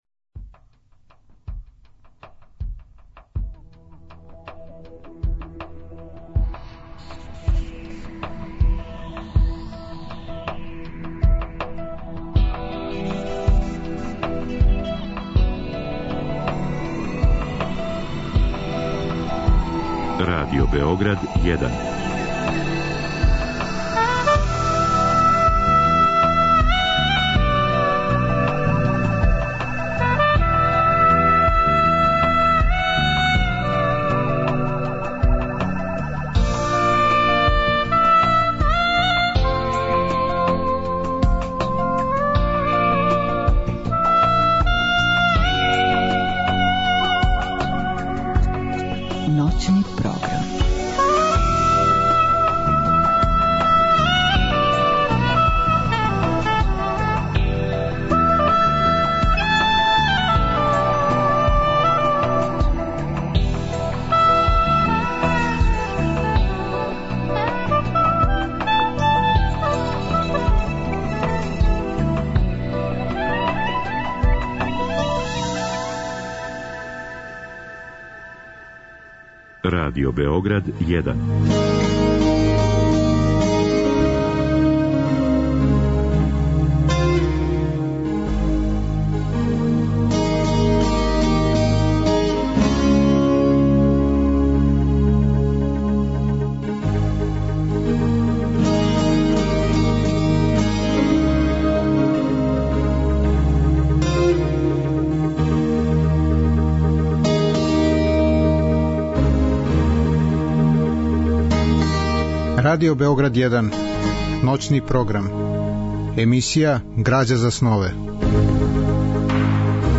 Разговор и добра музика требало би да кроз ову емисију и сами постану грађа за снове.
У трећем сату емисије слушаћемо одабране фрагменте књиге Знакови поред пута Иве Андрића, у интерпретацији драмског уметника Милана Цација Михаиловића.